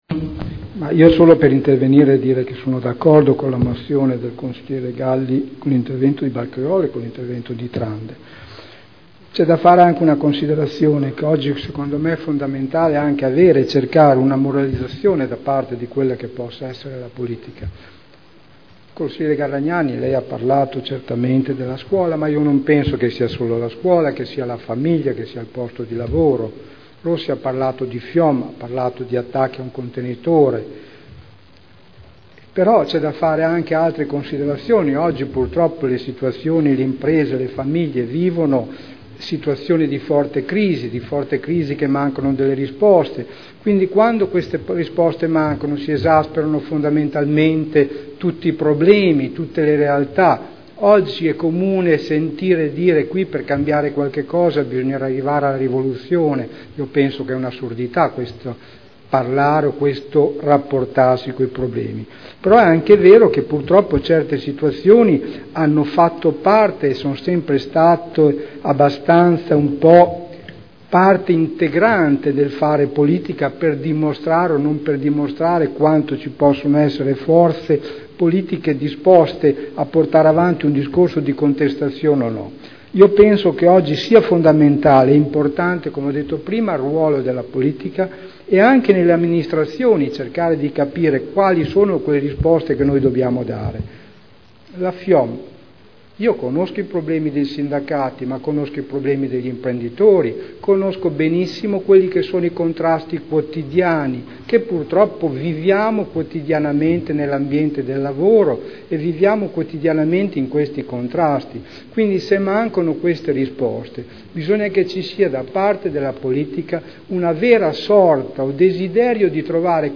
Sergio Celloni — Sito Audio Consiglio Comunale